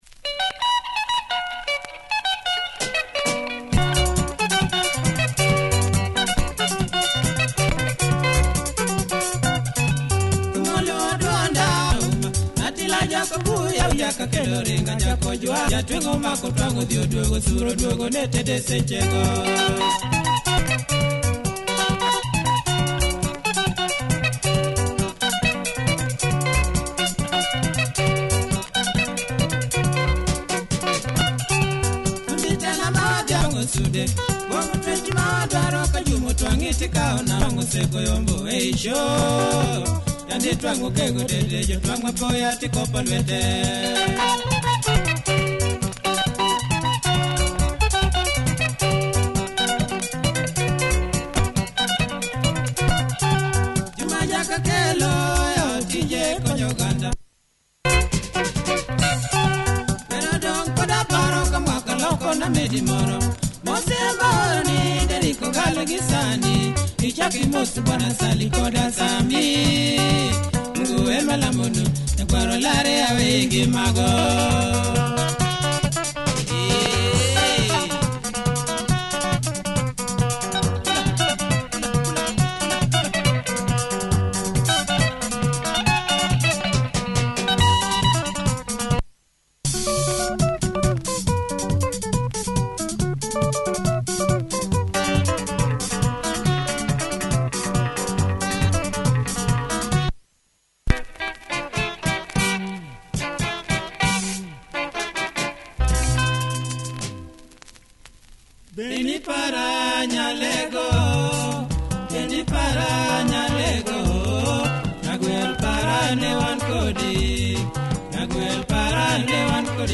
Pumping luo benga, check audio of both sides! https